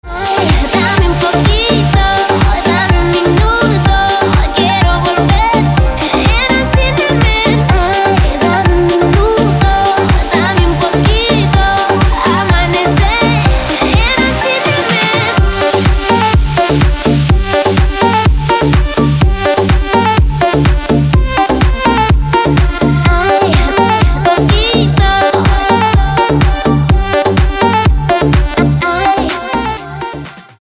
• Качество: 256, Stereo
поп
женский вокал
веселые
dance
Зажигательная летняя песенка в ремиксе